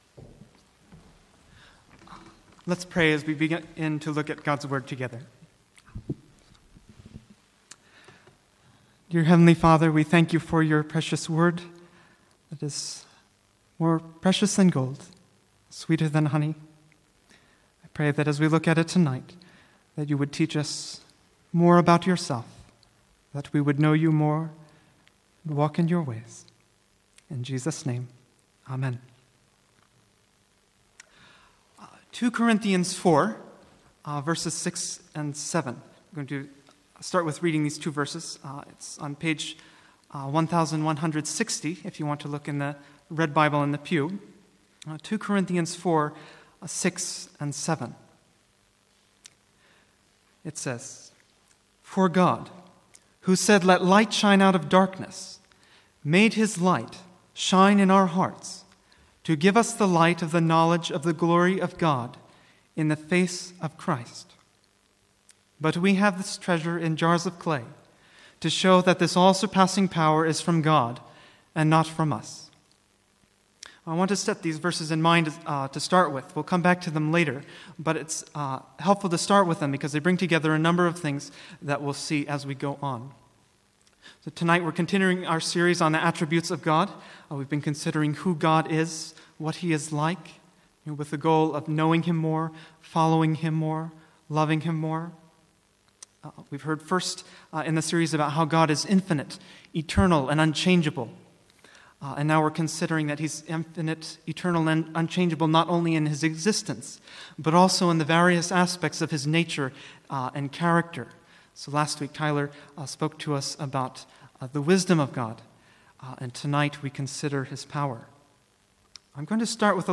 Sermons | St Andrews Free Church
From the Sunday evening series on Question 4 of the Westminster Shorter Catechism - "What is God?"